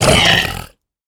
sounds / mob / piglin / death2.ogg